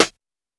Snares
just blaze tapsnare.WAV